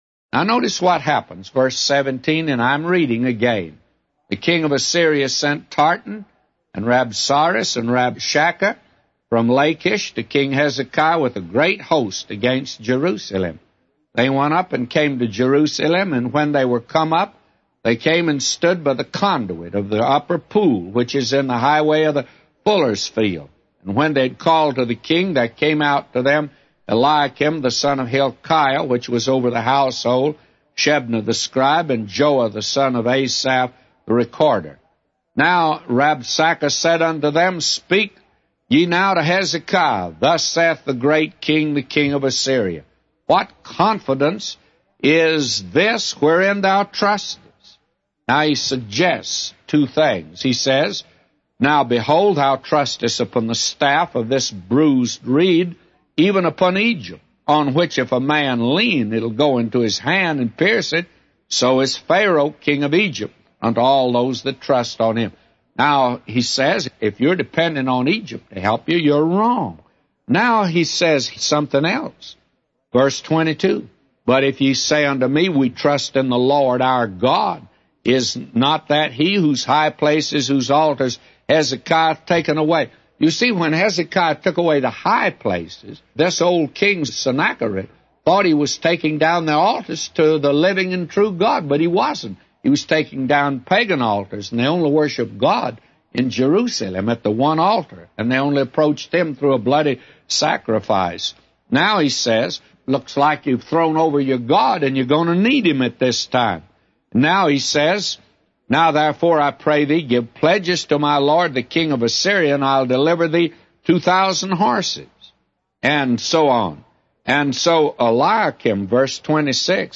A Commentary By J Vernon MCgee For 2 Kings 18:17-999